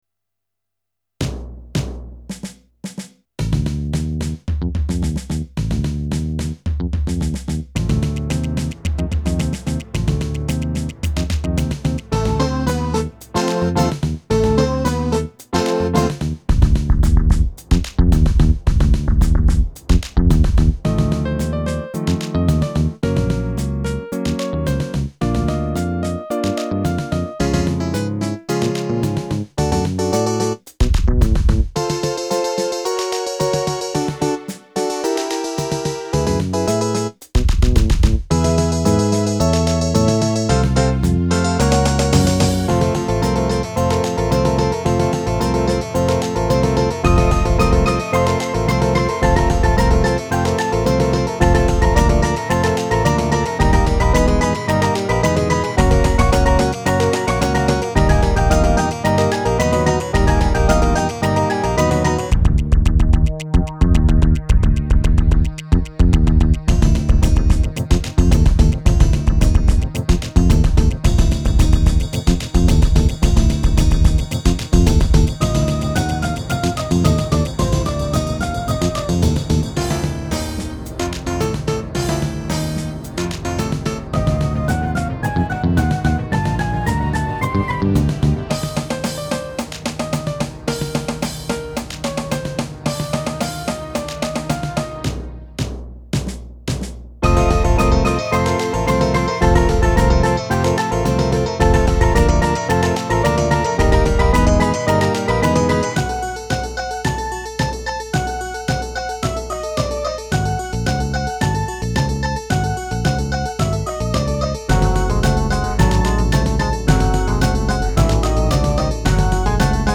Solid infection rhythms with an international flavour